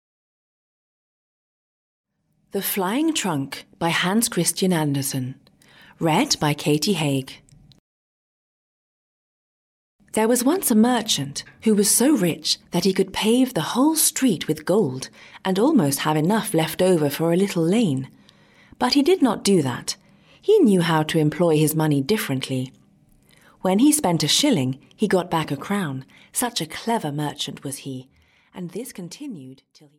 Audiobook The Flying Trunk written by Hans Christian Andersen.
Ukázka z knihy